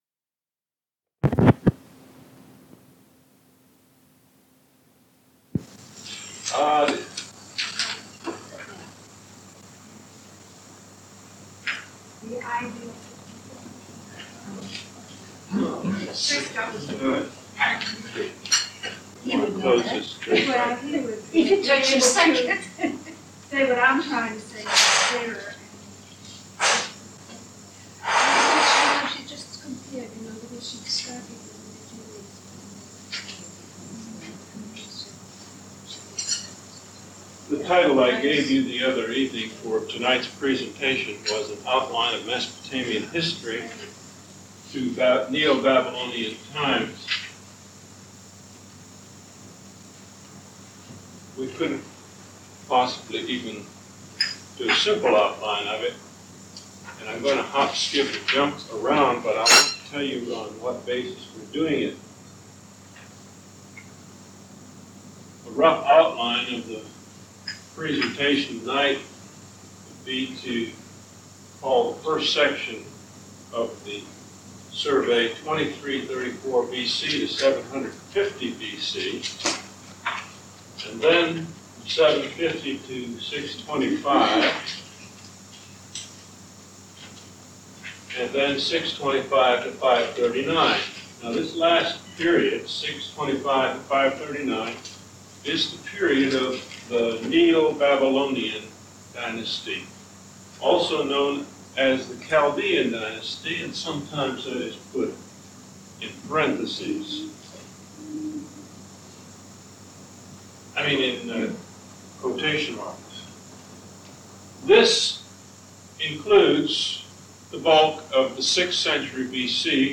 An Archaeological Interpretation of Babylon in the 6th Century B. C.; Humans, Deities, and their relationships, lecture #2: An Outline of Mespotamian History to the Neo-Babylonian Period